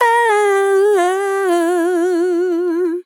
Categories: Vocals Tags: AHH, DISCO VIBES, dry, english, female, fill, sample, WHOAH
POLI-Vocal-Fills-120bpm-Fm-4.wav